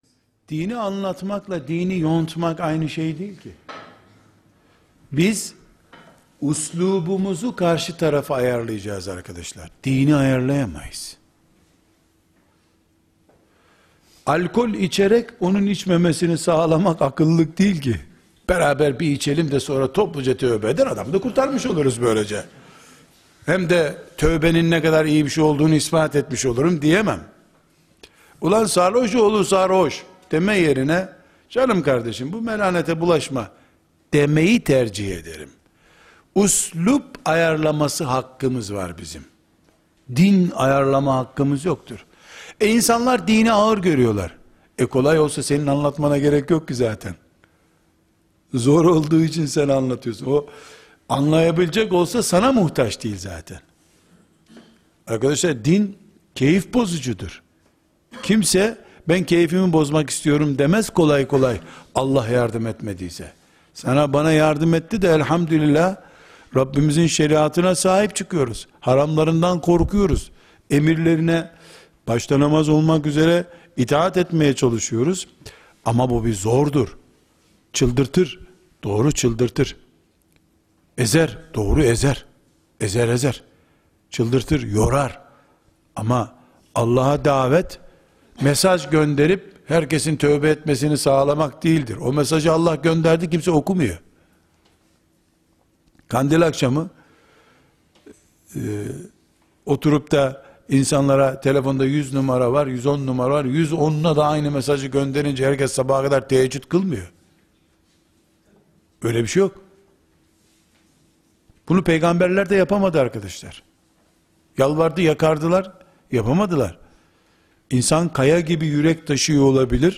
2. Soru & Cevap